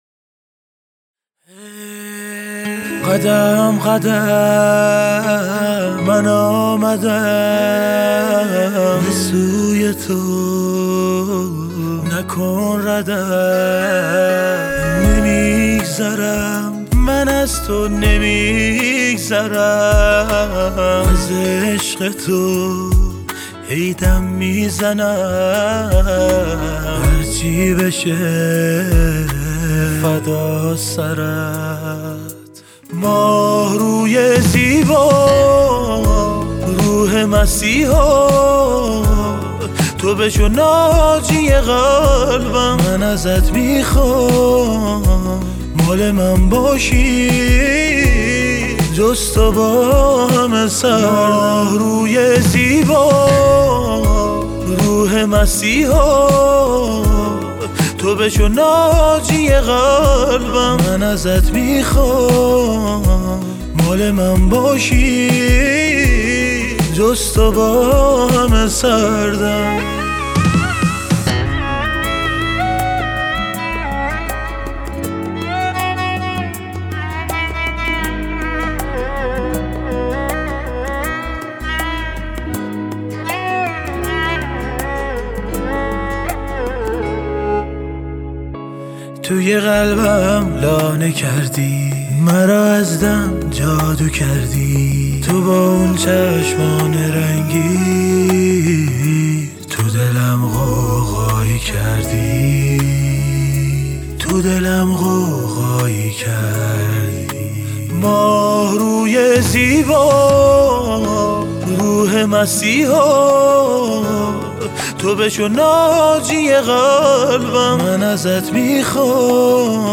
آهنگ با صدای زن
آهنگ غمگین آهنگ محلی